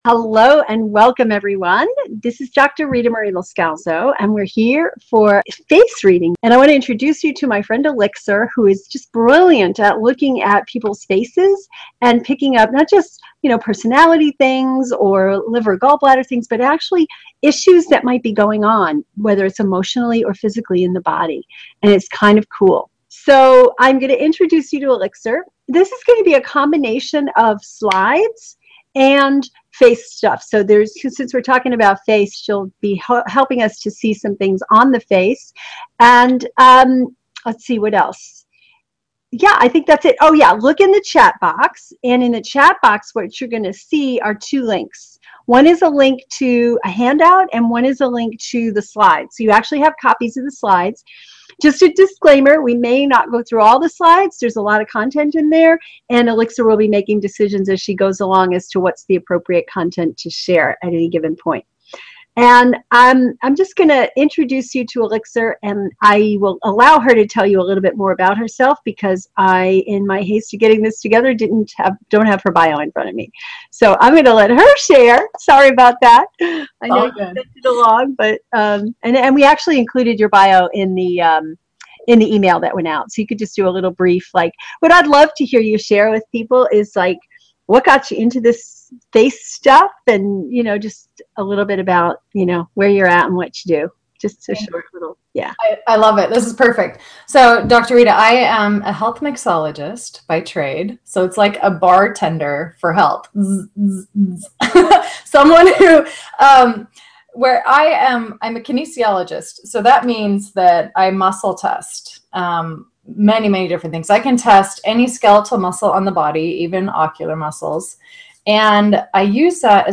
In this video, watch the presentation and learn about what the lines on your face really mean, what blemishes and acne mean, and how to conduct a faceology nutritional evaluation.